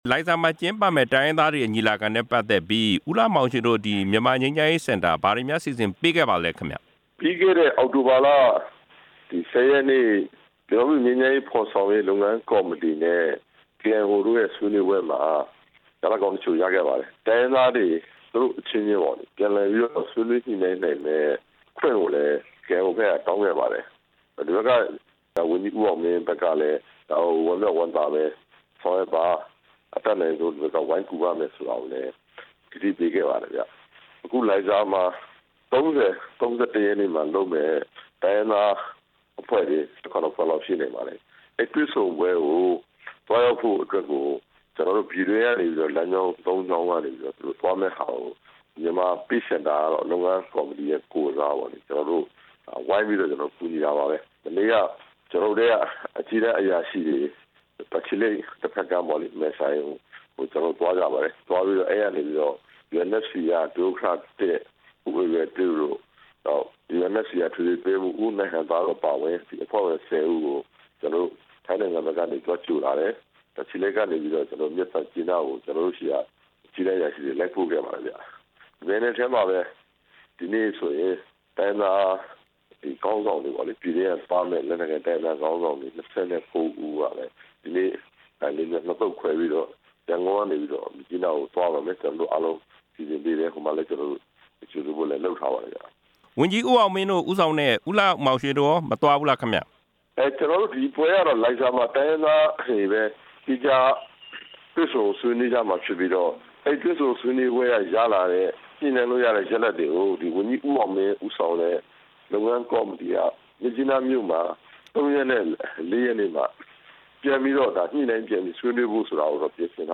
ဆက်သွယ်မေးမြန်းထားတာကို နားဆင်နိုင်ပါတယ်။